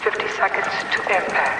Woman over PA - 50 seconds to impact.ogg
Original creative-commons licensed sounds for DJ's and music producers, recorded with high quality studio microphones.
[woman-over-pa]-50-seconds-to-impact_mvk.mp3